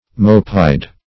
Mope-eyed \Mope"-eyed`\, a.